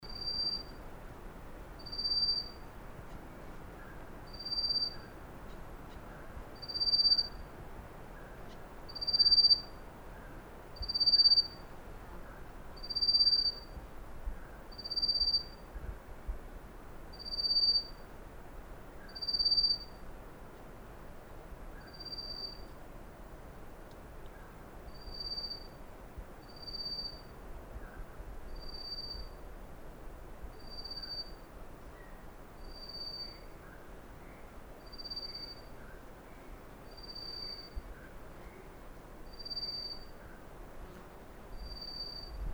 Pouillot à gorge blanche ( Abroscopus albogularis ) ssp fulvifascies
Chant enregistré le 08 mai 2012, en Chine, province du Fujian, réserve de Dai Yun Shan.